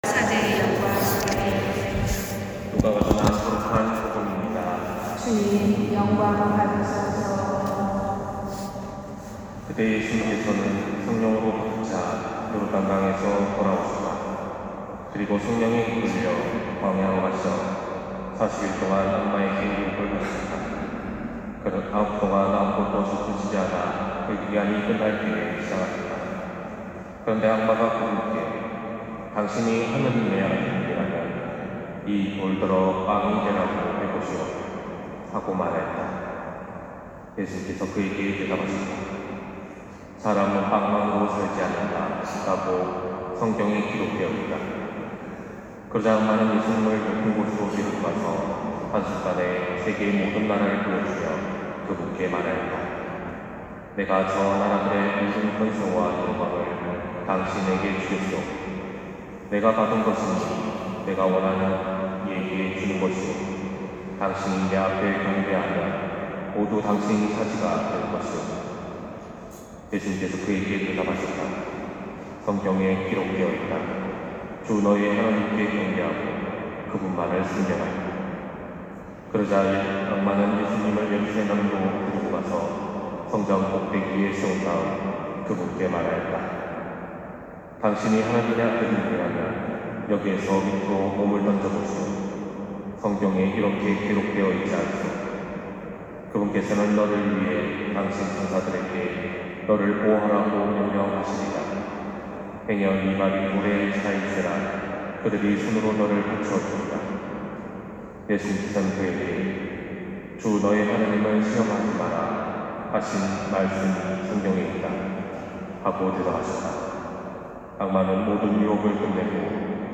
250308 신부님 강론말씀